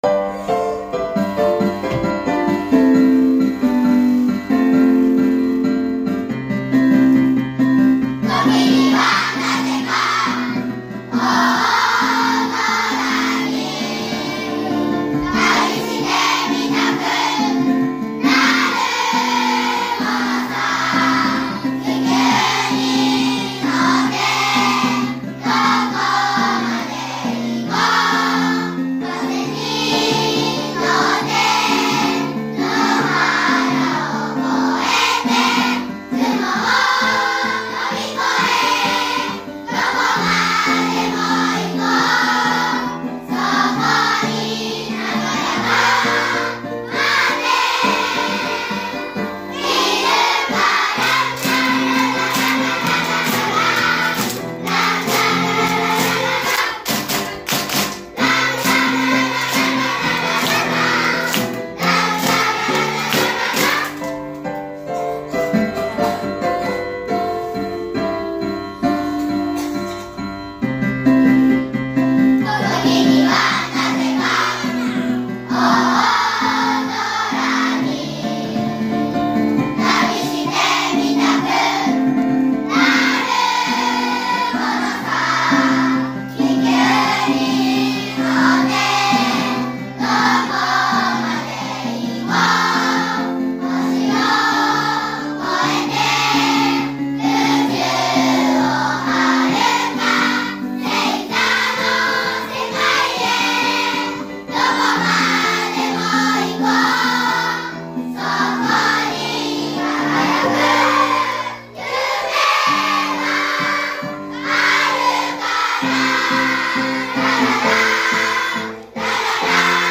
2年生♪1組2組♪